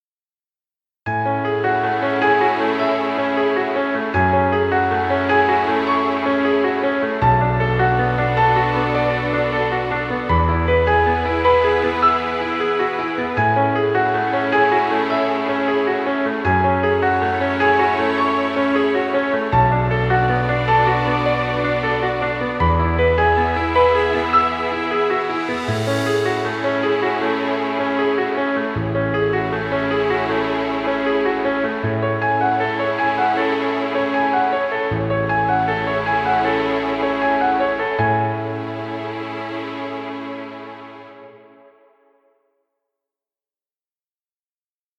Romantic music.